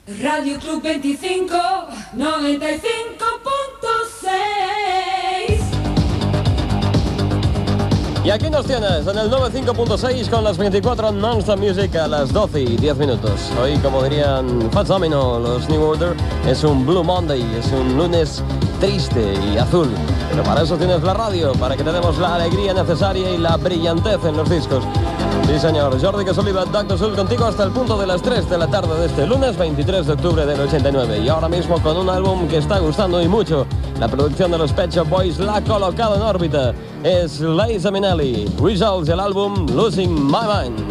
Indicatiu de l'emissora, hora, presentació i tema musical
Musical